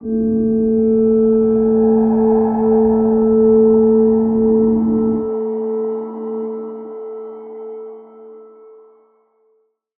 G_Crystal-A4-f.wav